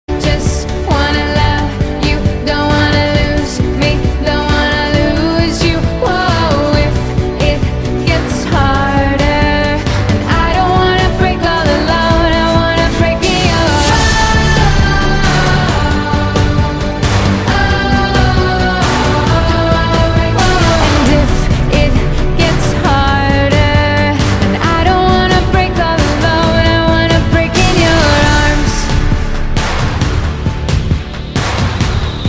欧美歌曲